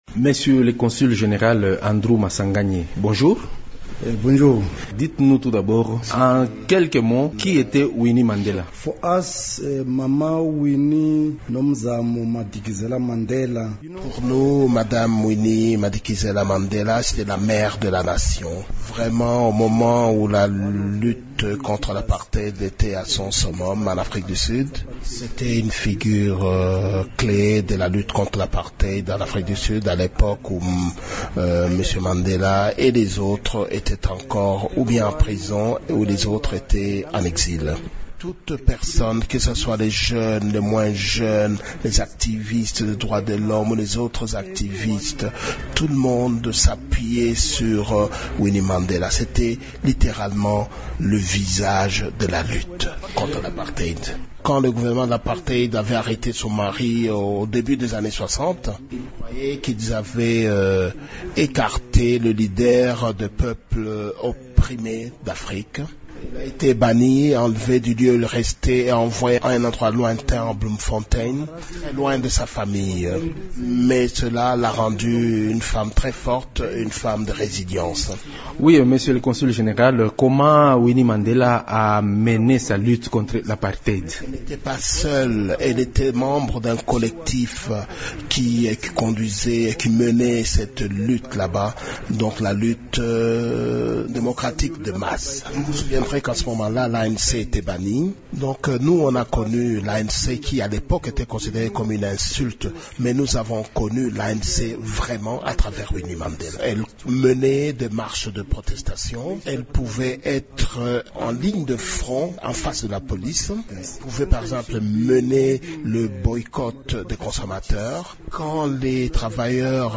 Dans une interview exclusive accordée ce mercredi à Radio Okapi, le consul général d’Afrique du Sud à Lubumbashi est revenu sur le parcours de Winnie Mandela qui est décédée le 02 avril dernier.